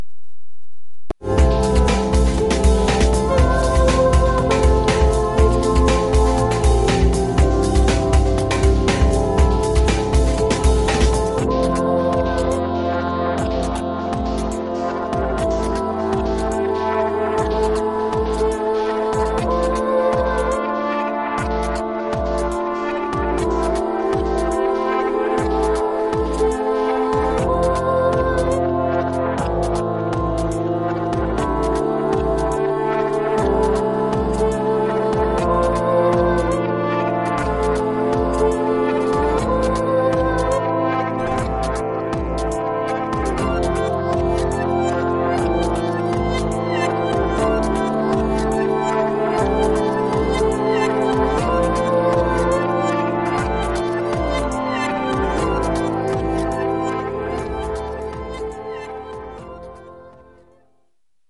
4 song EP of newest work by LA artist